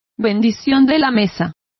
Complete with pronunciation of the translation of grace.